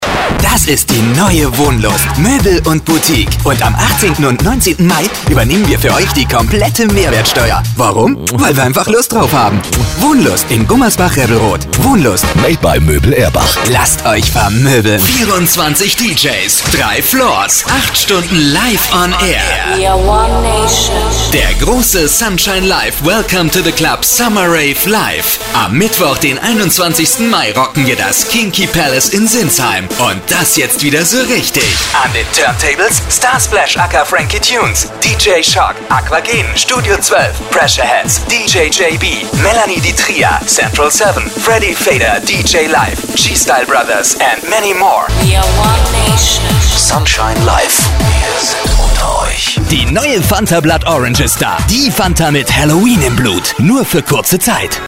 Sprecher englisch (usa) mit einem großen schauspielerischen Spektrum
Sprechprobe: Industrie (Muttersprache):
voice over artist english (us)